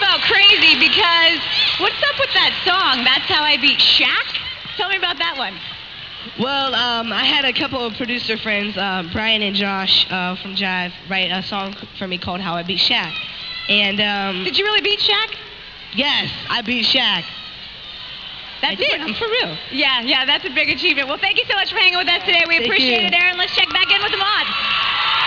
Sounds Recorded From TV Shows
I apologize for the quality of the sounds as I was not able to directly line-in record them, so they are slightly fuzzy